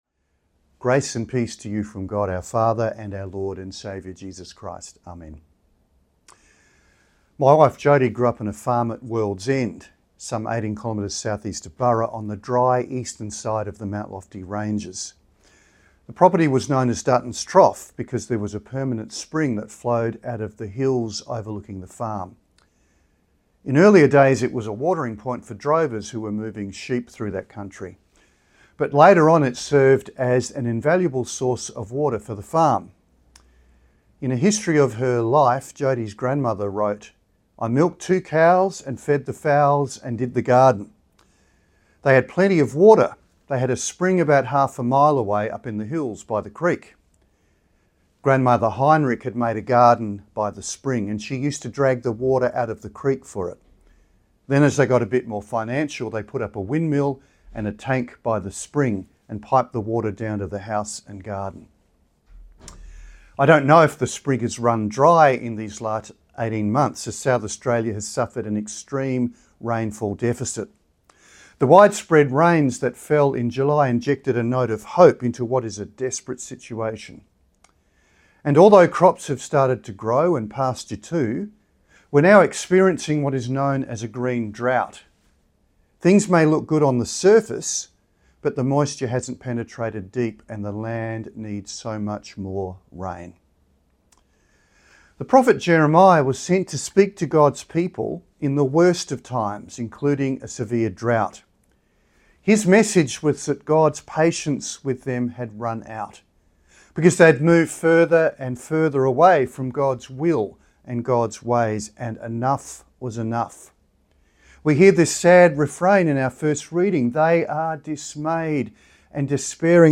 Sermon by Bishop Andrew Brook
Brook-Sermon.mp3